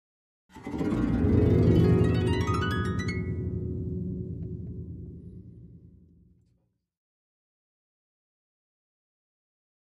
Harp, Low Strings Ascending Gliss, Type 4